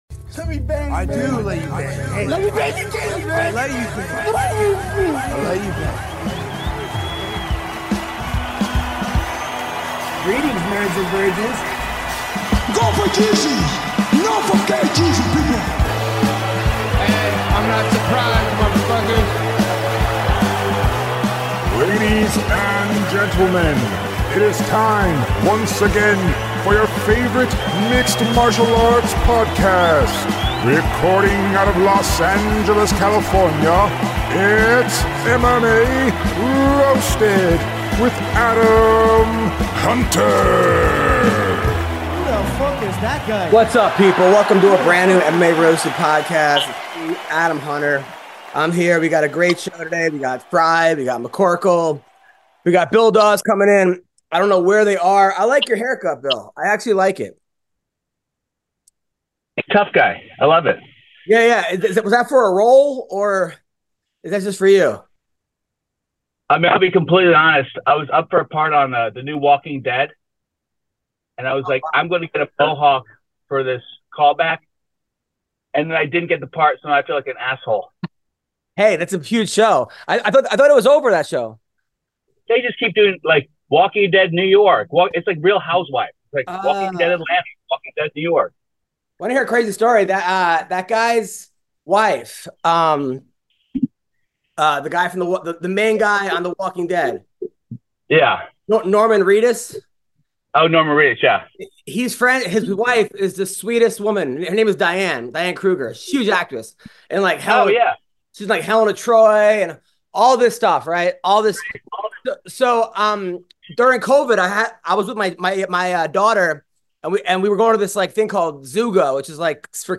On this episode of The MMA Roasted Podcast, the crew speaks to UFC fighter Grant Dawson and discusses MMA news!